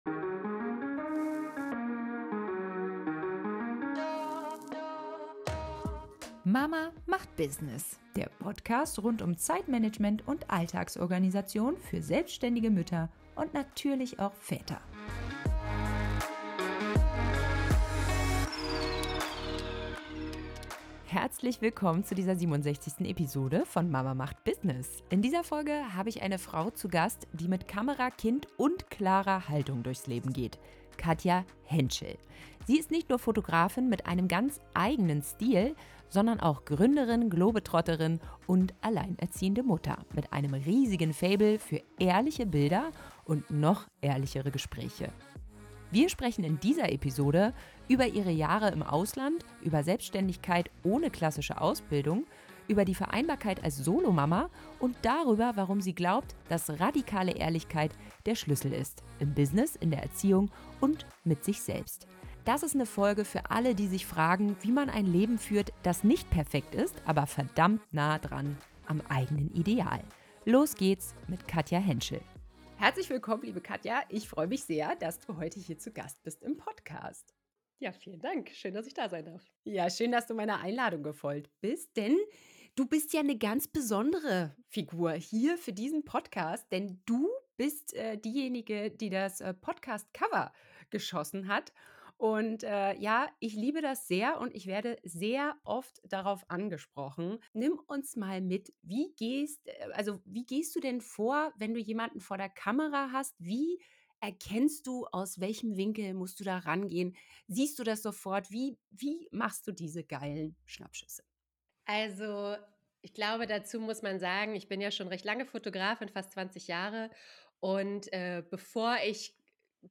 Ein ehrliches Gespräch über Mut, Selbstständigkeit und das Leben zwischen Schulferien und Shootings.